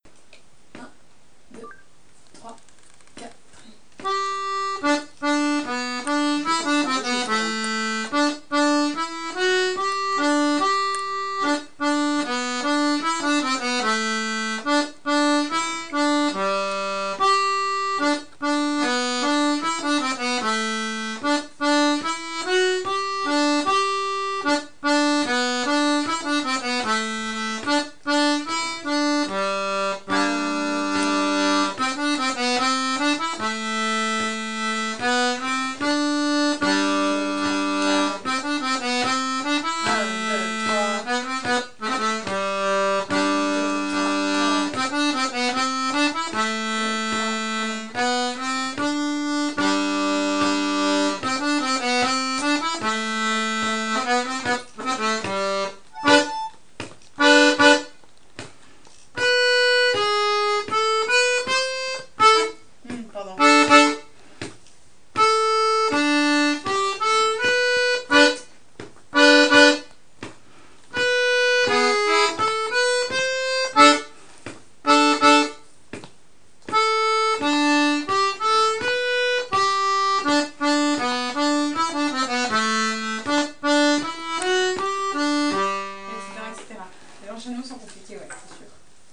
l'atelier d'accordéon diatonique
travail sur Juokin Toinen Jenkka, scottish en cortège finlandaise